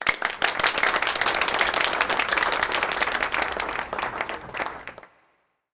Applause Sound Effect
Download a high-quality applause sound effect.
applause-4.mp3